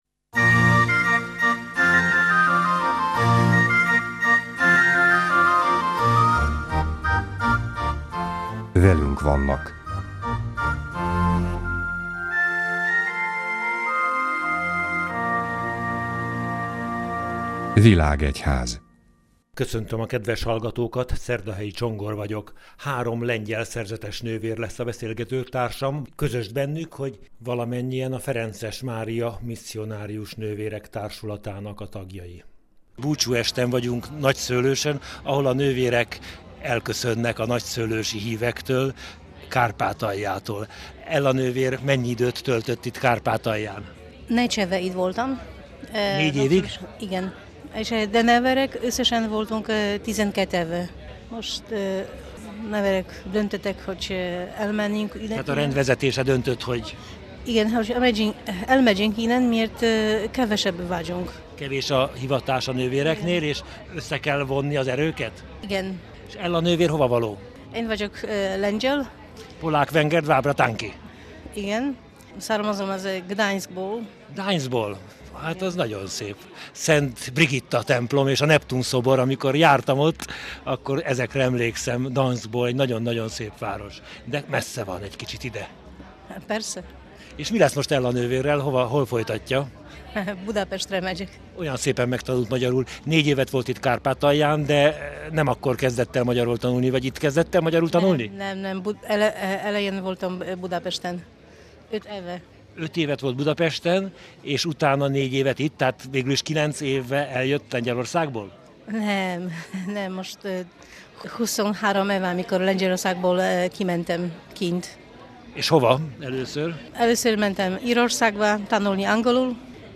Tizenkét év után sajnos elhagyják Kárpátalját a ferences kisebb testvérekkel Nagyszőlősön szorosan együttműködő Ferences Mária Misszionárius Nővérek Társulata tagjai. Búcsúestjükön készített beszélgetéseket a Katolikus Rádió.